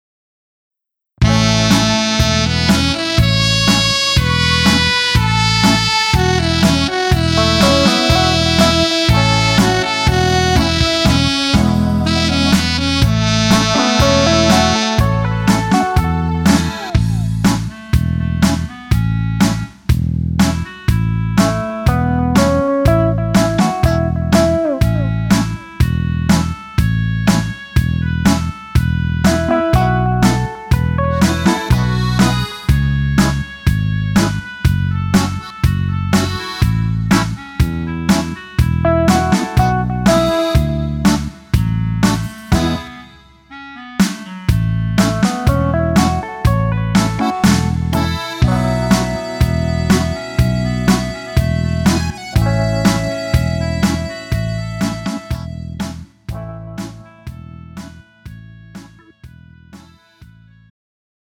음정 -1키 2:29
장르 가요 구분 Pro MR